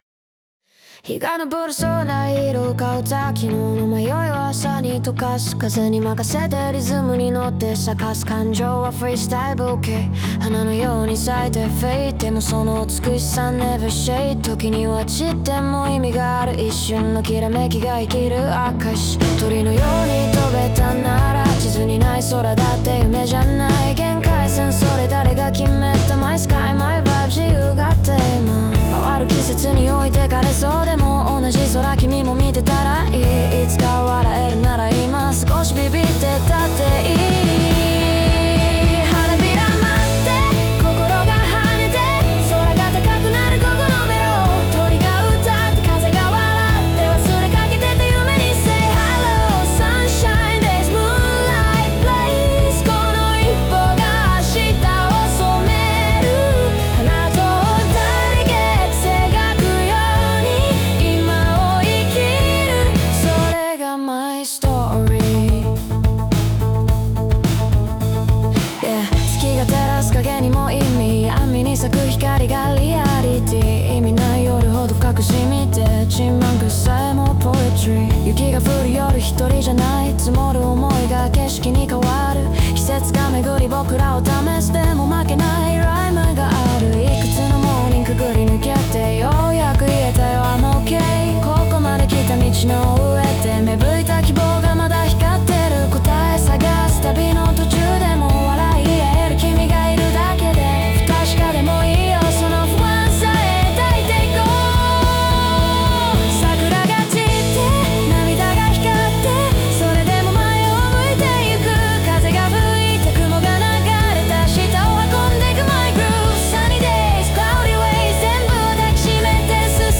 j-pop